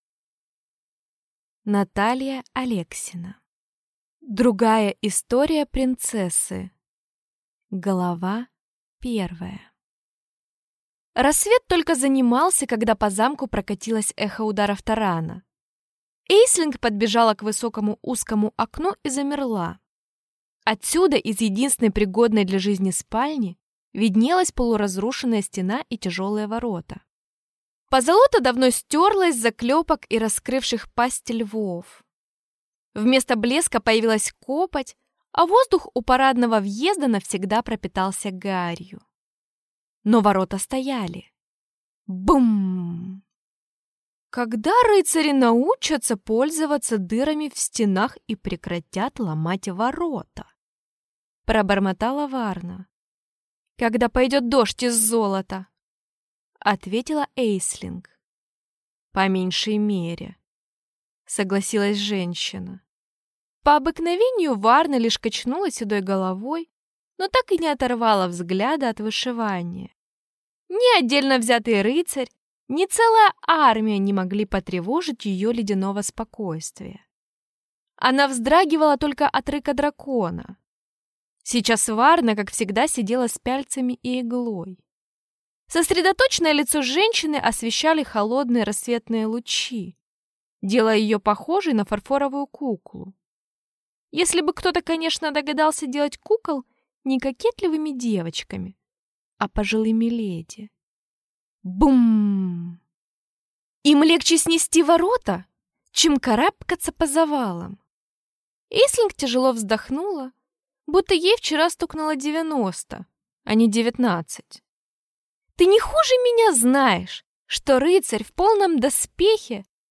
Аудиокнига Другая история принцессы | Библиотека аудиокниг
Прослушать и бесплатно скачать фрагмент аудиокниги